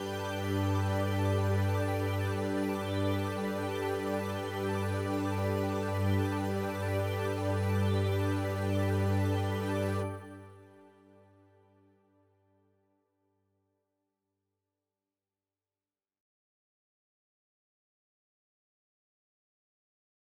beam blue bright clear galaxy gleam glimmer glisten sound effect free sound royalty free Memes